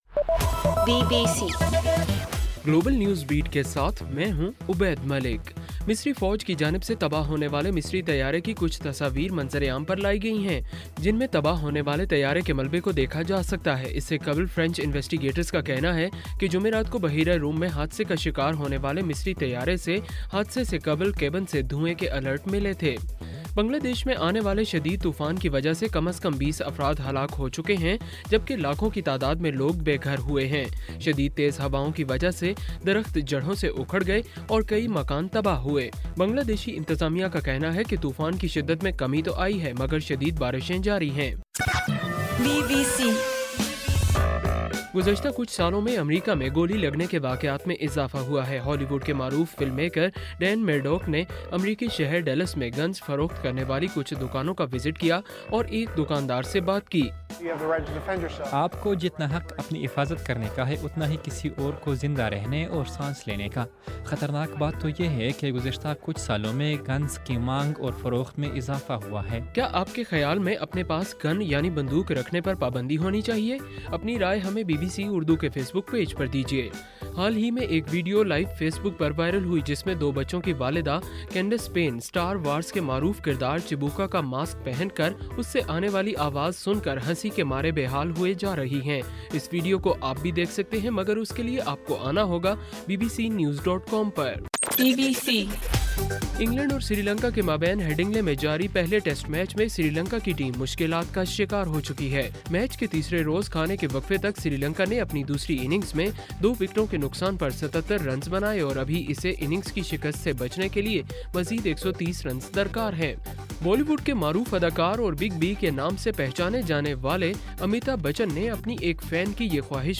بُلیٹن